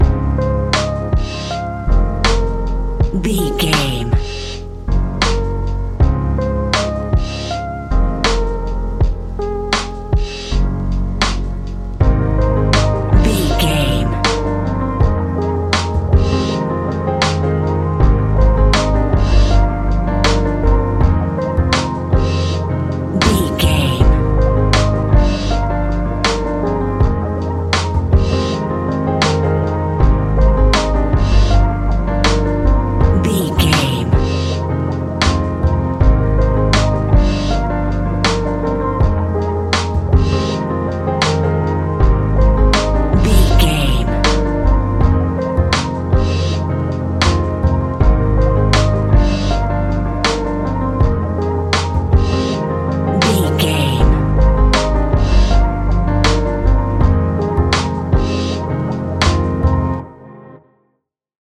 Ionian/Major
F♯
chilled
laid back
Lounge
sparse
new age
chilled electronica
ambient
atmospheric